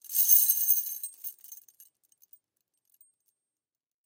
Звон цепей и звук